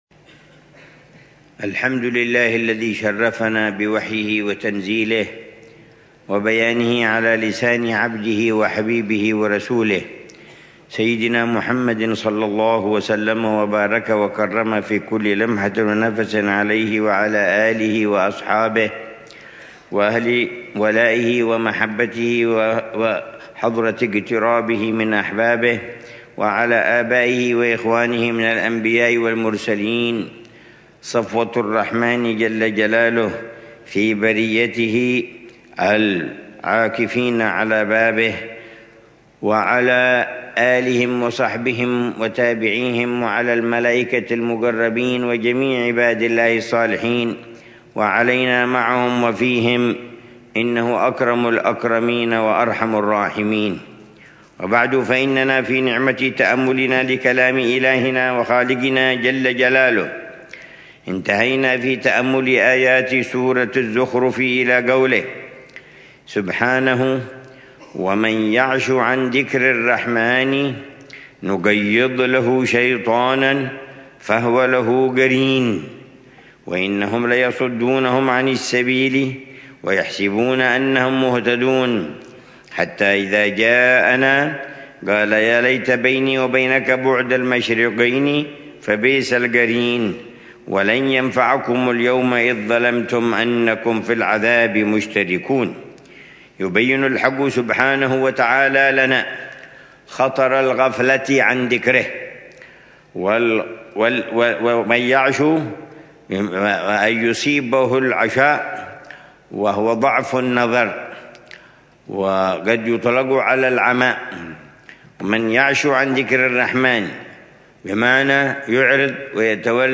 الدرس الخامس من تفسير العلامة عمر بن محمد بن حفيظ للآيات الكريمة من سورة الزخرف، ضمن الدروس الصباحية لشهر رمضان المبارك من عام 1446هـ